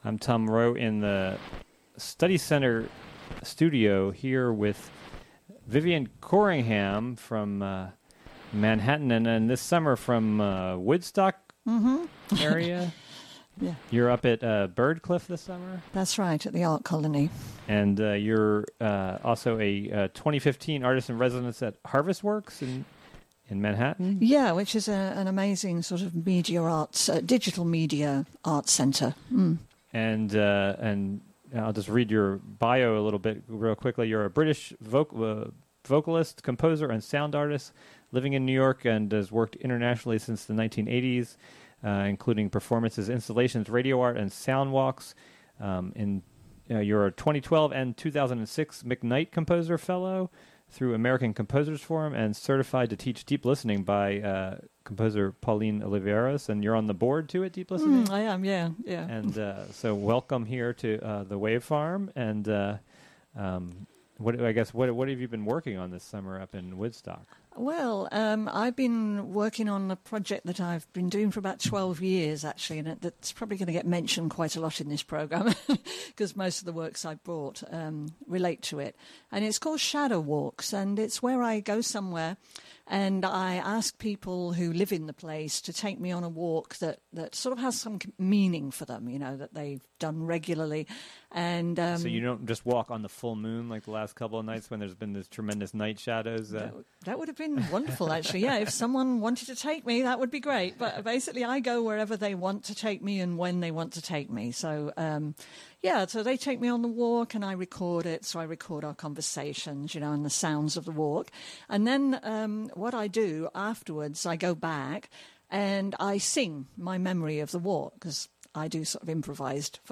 Interviewed
Recorded Aug. 29, 2015 at Wave Farm.